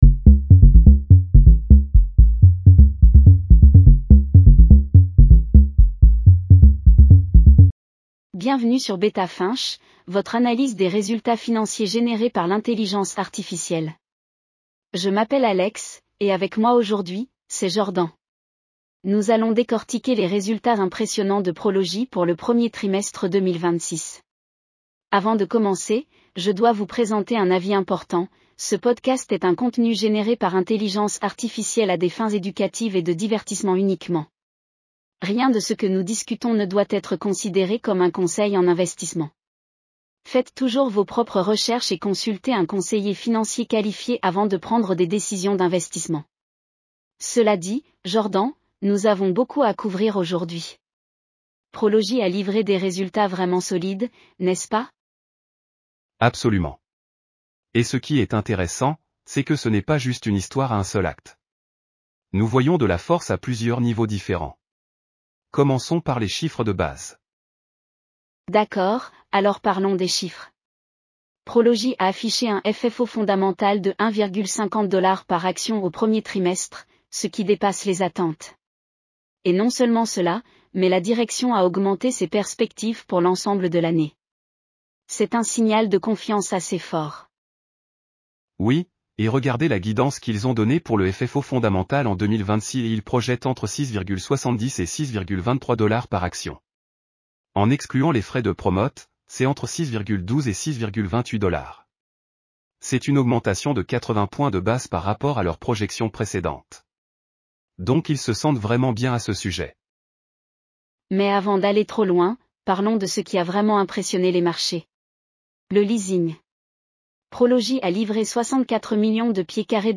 Bienvenue sur Beta Finch, votre analyse des résultats financiers générée par l'intelligence artificielle.